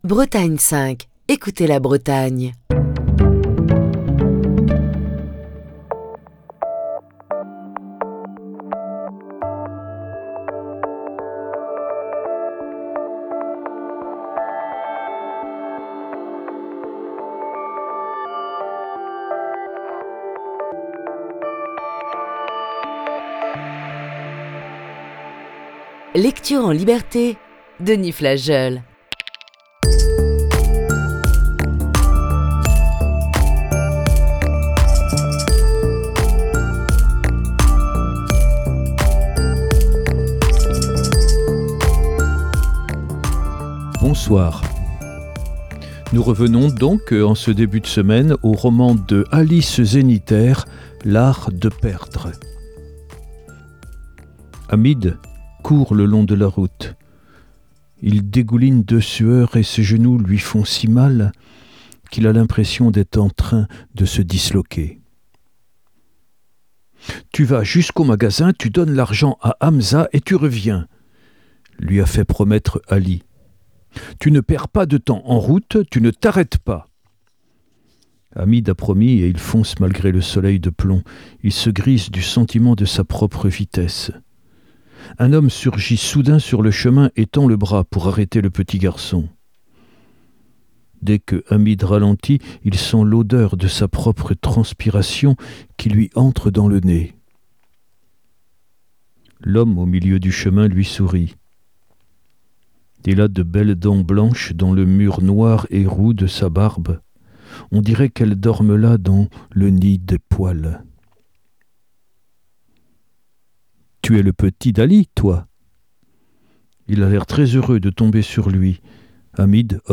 Voici ce lundi la lecture de la onzième partie de ce récit.